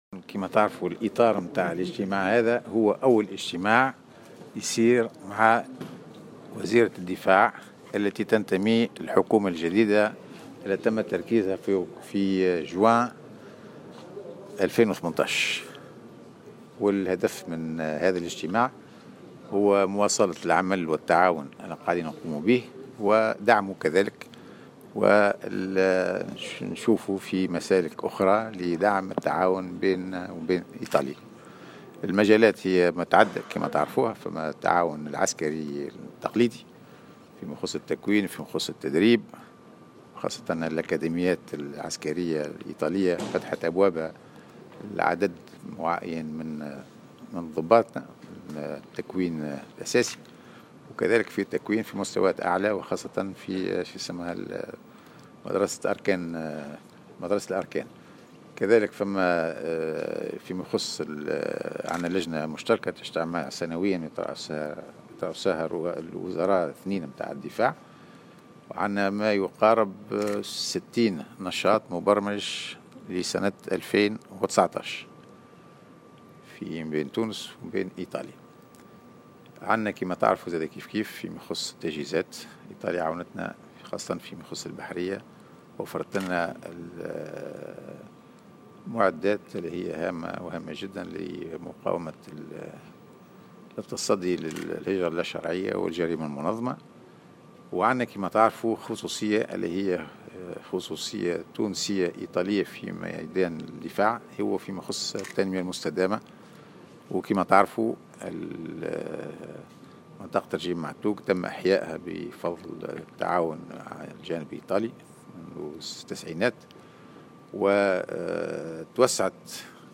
وأكد في تصريحات لمراسل "الجوهرة أف أم"، على هامش لقاء جمعه مع نظيرته الإيطالية اليزابيتا ترنتا بمقر وزارة الدفاع ان هذا الاجتماع هو الأول من نوعه بعد تشكيل حكومة ايطالية جديدة في ايطاليا مشددا على أنه لا يوجد أي تغيير بخصوص التعاون بين البلدين بعد تشكيل هذه الحكومة.